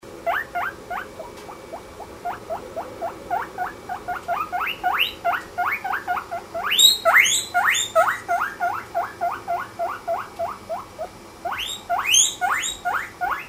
Pfeifen: cuii, cuii, cuii !
Meerschweinchen pfeifen meist laut, wenn sie nach etwas zu Fressen rufen oder in den Freilauf möchten ;-)
wheek_wheek.mp3